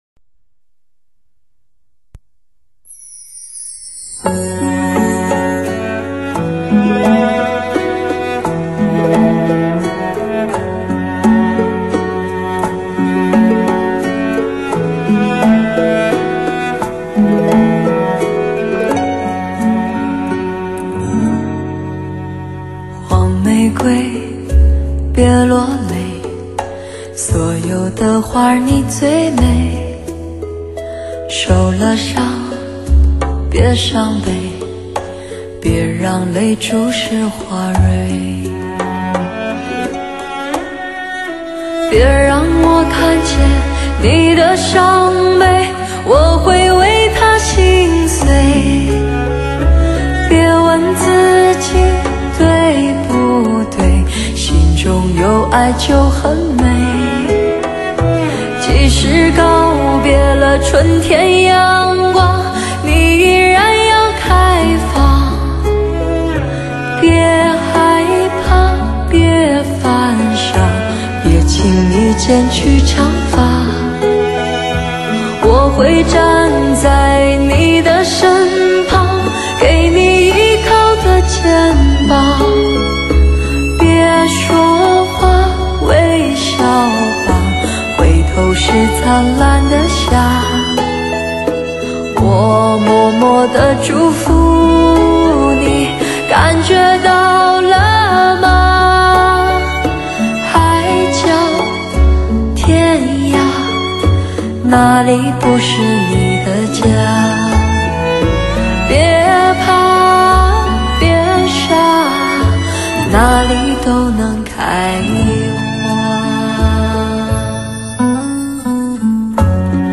Surround 7.1三维环绕HI-FI最经典人声高临场感音效,
STS+SRS全方位 环绕,HI-FI AUTO SOUND 专业天碟
Live高临场感CD.....